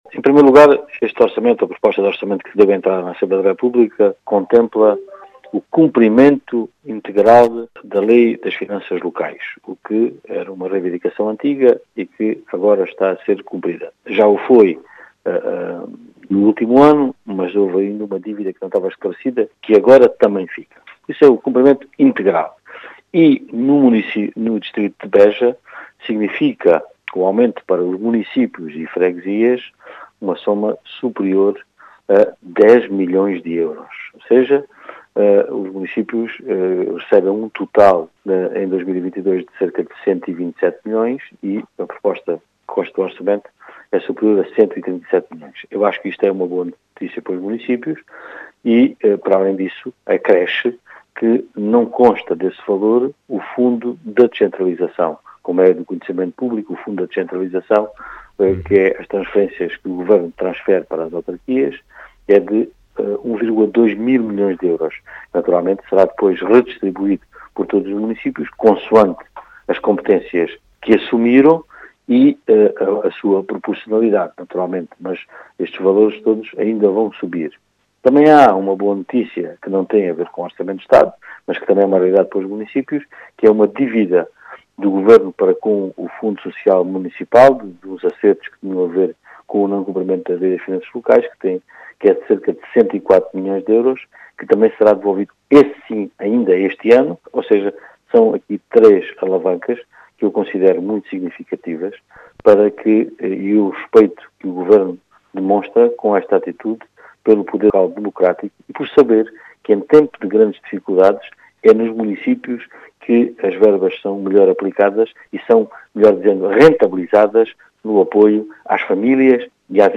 As explicações foram deixadas na Rádio Vidigueira, por Pedro do Carmo, deputado do PS eleito por Beja, dizendo tratar-se de uma “boa noticia para os municipios”.